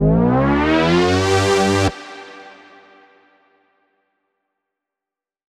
Index of /musicradar/future-rave-samples/Siren-Horn Type Hits/Ramp Up
FR_SirHornC[up]-G.wav